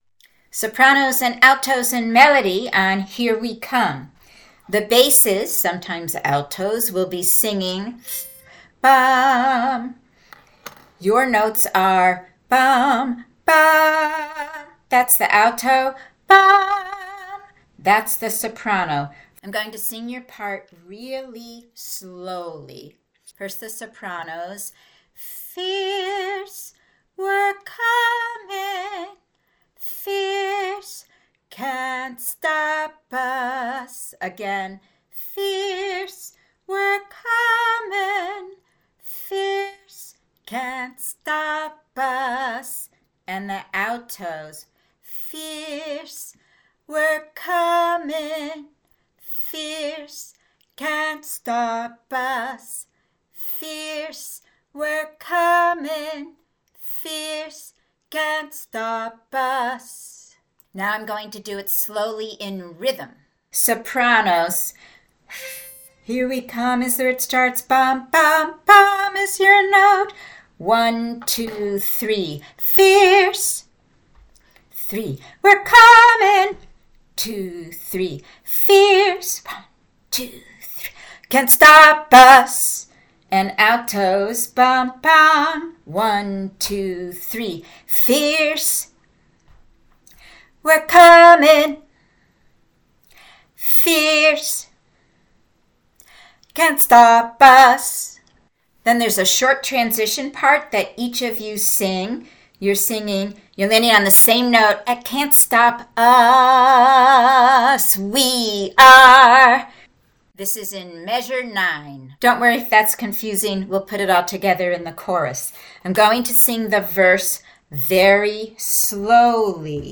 Here We Come sop-alto-melody.mp3